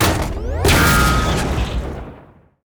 metal_crate_explosion_01.ogg